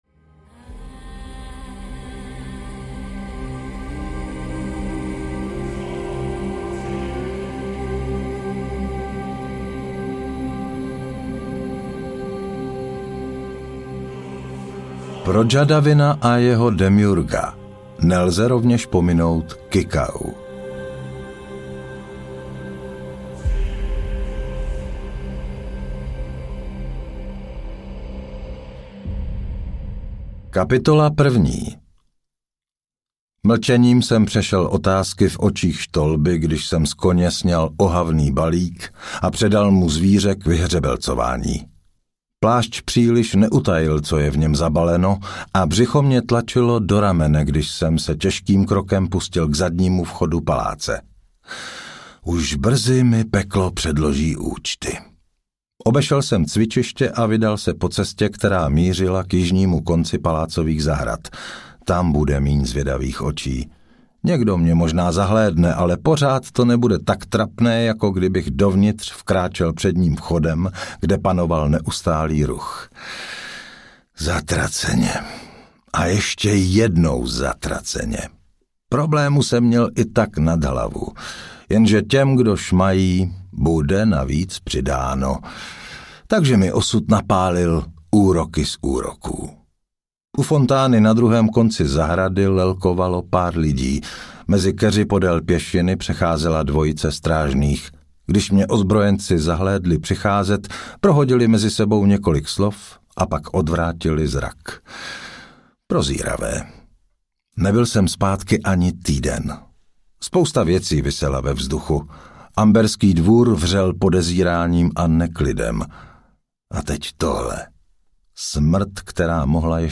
Amber 3 - Znamení jednorožce audiokniha
Ukázka z knihy